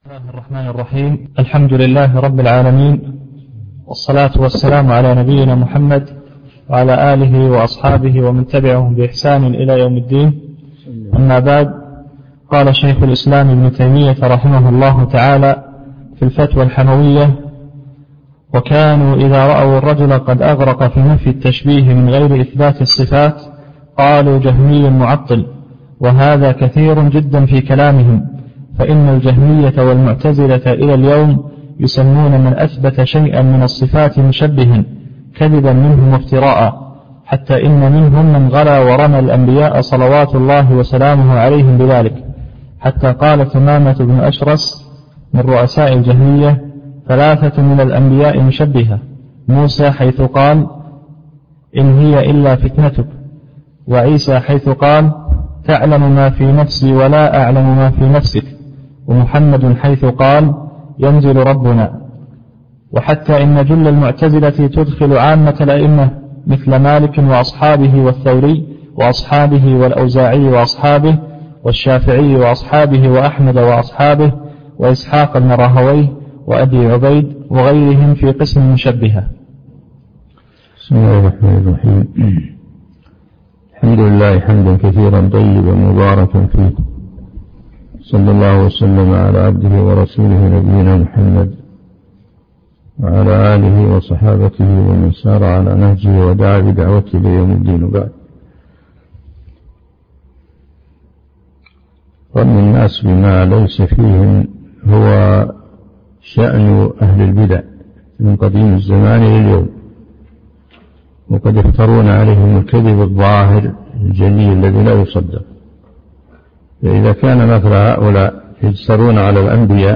الدرس (16) شرح الفتوى الحموية